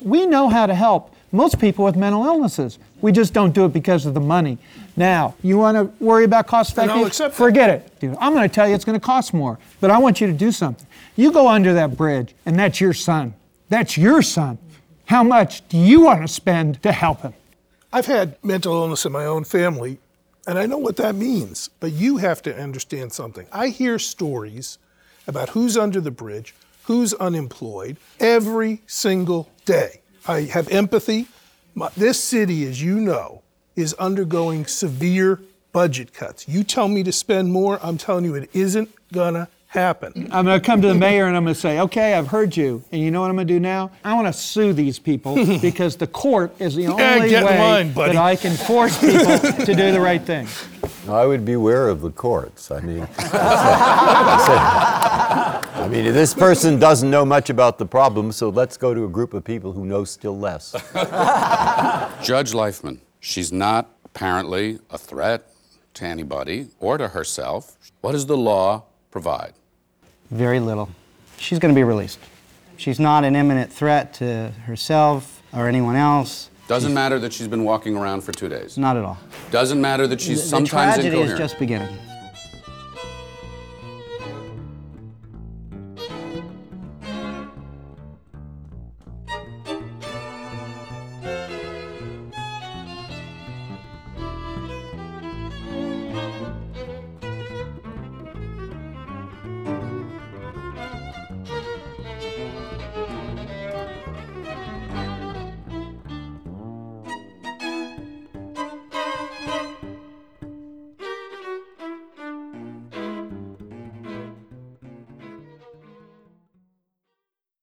Frank Sesno moderates an engaging conversation sparked by a diverse panel including: Supreme Court Justice Stephen Breyer, Nobel Laureate Dr. Eric Kandel and other physicians, policy makers and mental health professionals, some of whom themselves struggle with mental illness.
This radio special airs in conjunction with